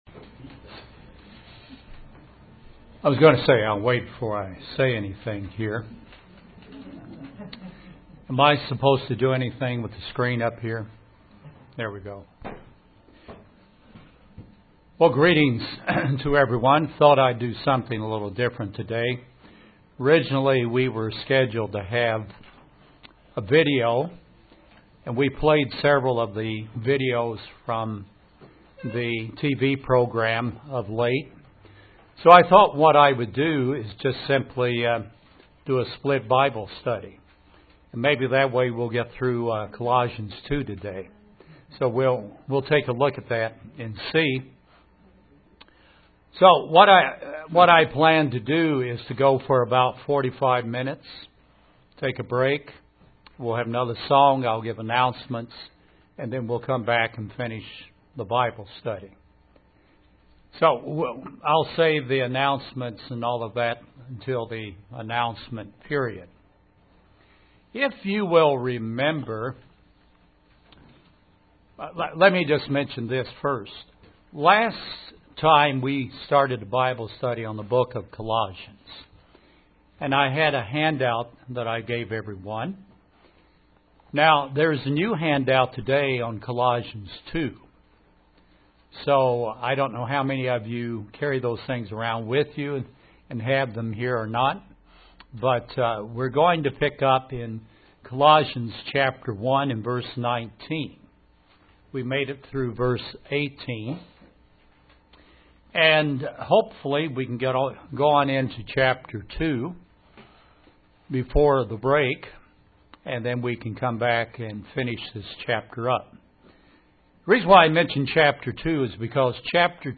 A Bible study on the book of Colossians.